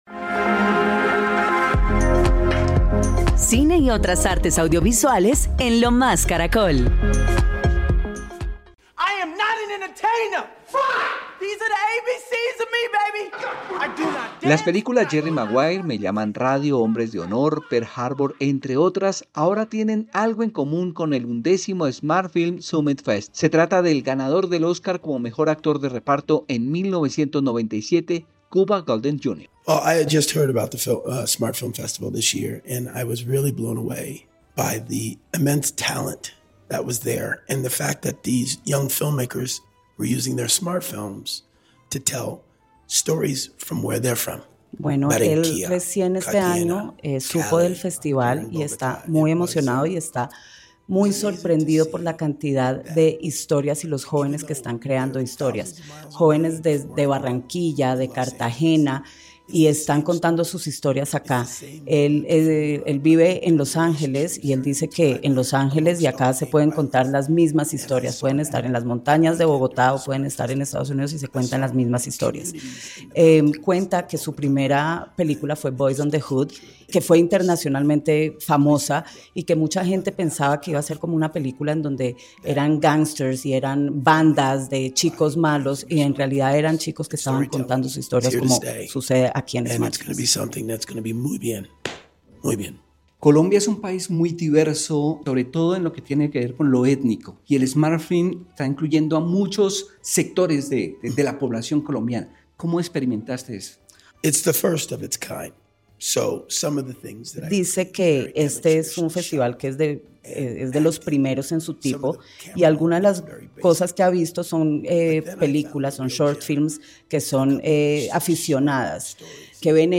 En diálogo con CARACOL RADIO, el actor exaltó el trabajo que Smartfilms hace para promover la cinematografía con celulares.